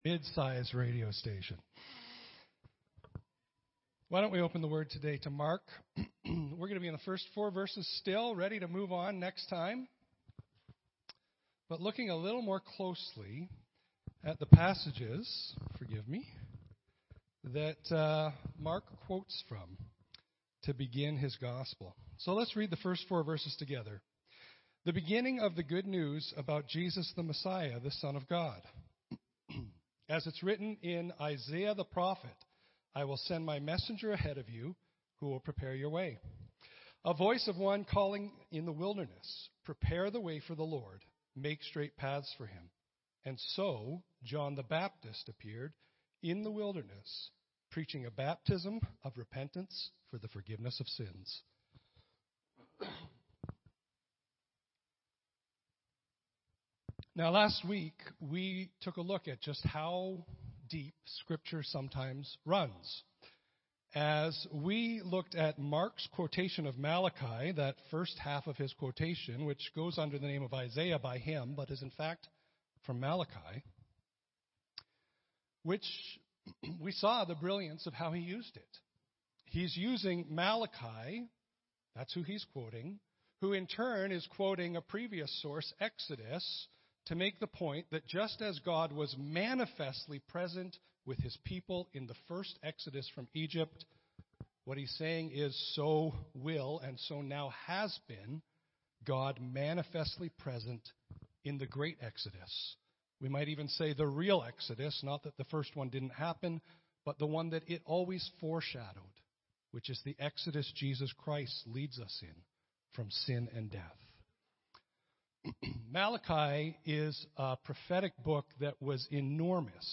Audio Sermon Library